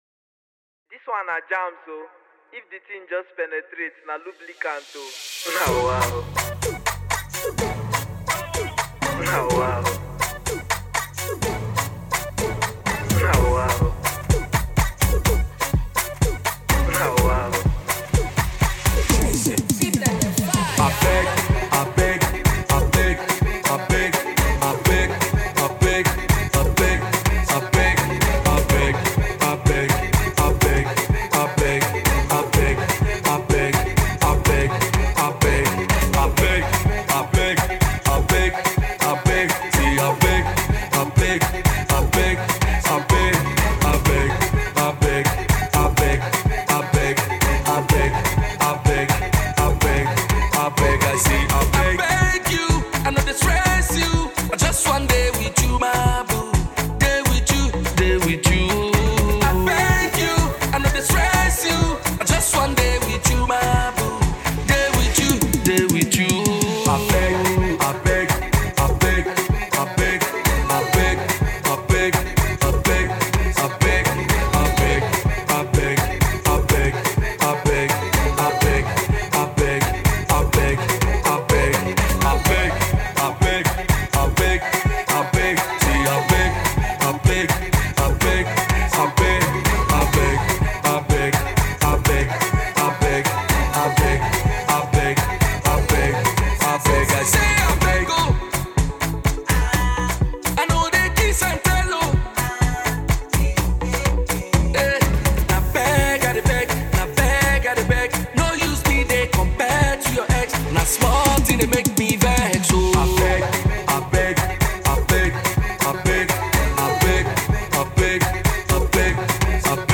It is a love song to your lover.